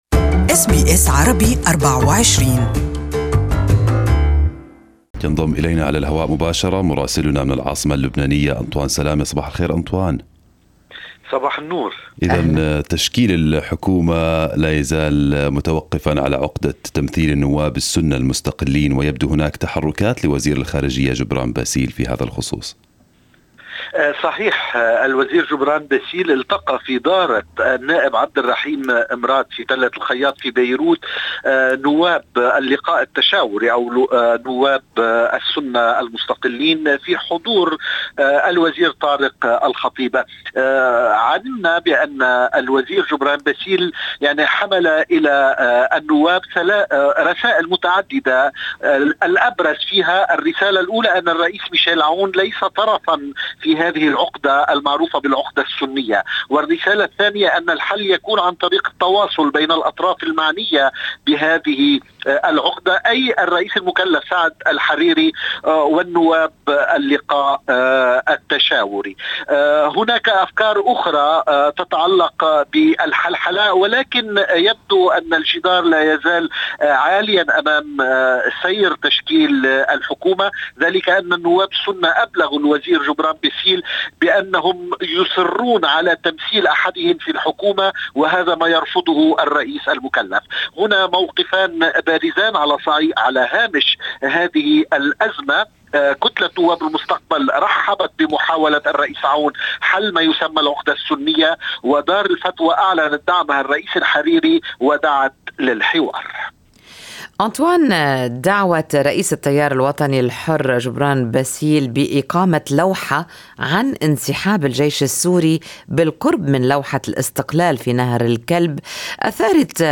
Our reporter in Beirut has the details